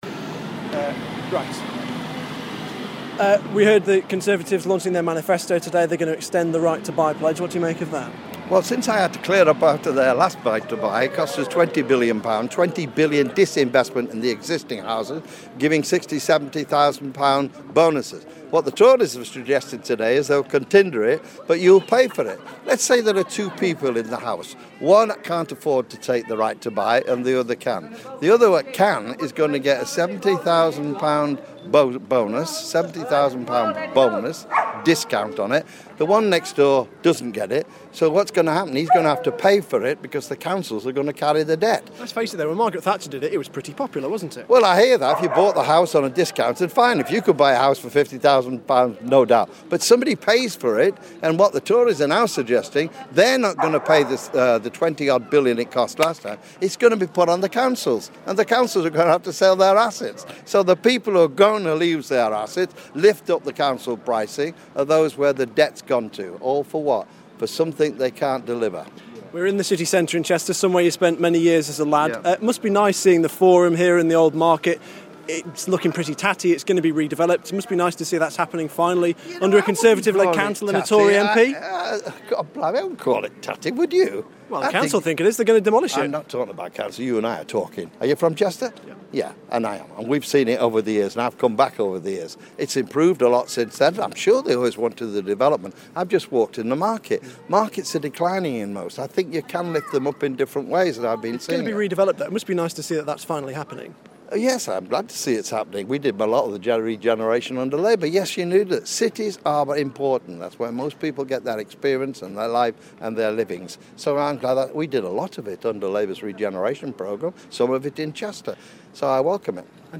John Prescott interview
John Prescott talking to me on a visit to Chester ahead of the general election on 14 April 2014.